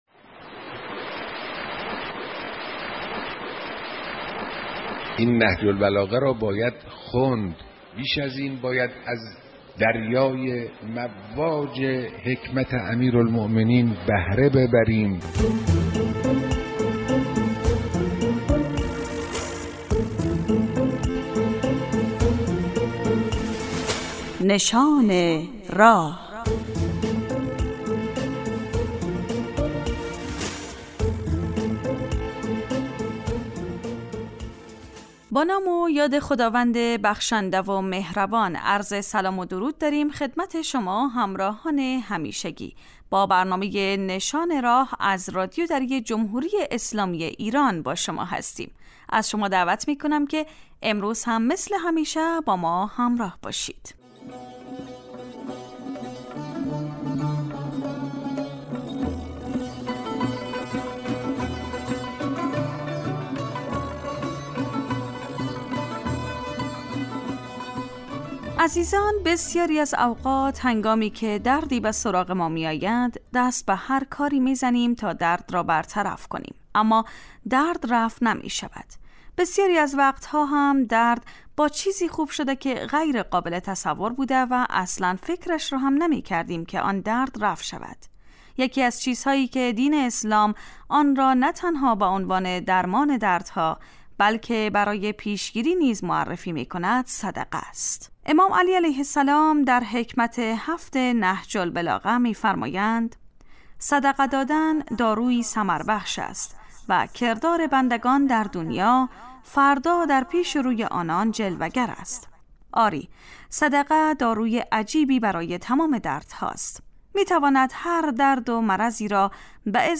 نشان راه برنامه در 15 دقیقه در ساعت 7.30 صبح روز یکشنبه با موضوع پندها و حکمتهای نهج البلاغه به صورت مجله ای پخش می شود.